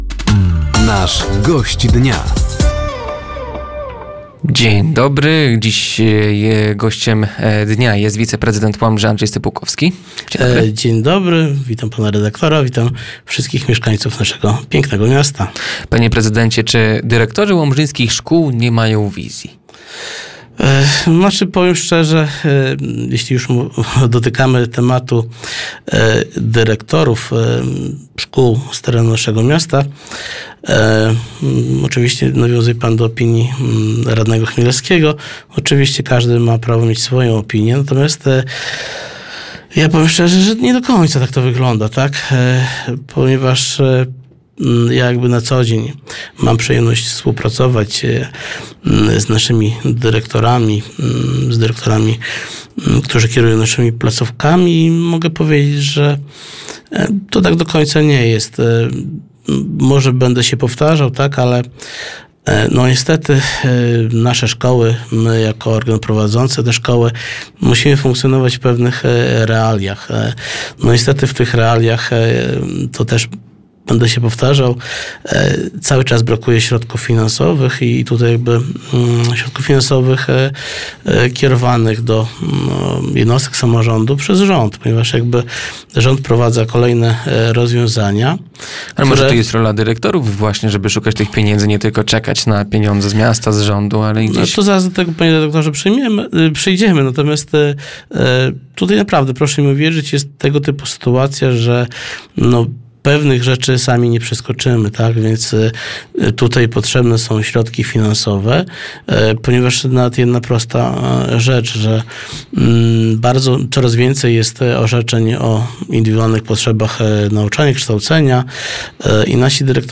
Plany powstania parkingu Park & Ride w Łomży, powrót strefy płatnego parkowania w mieście od 2027 roku oraz poziom łomżyńskiej edukacji – to główne tematy rozmowy podczas audycji ,,Gość Dnia”. Studio Radia Nadzieja odwiedził Andrzej Stypułkowski, wiceprezydent Łomży.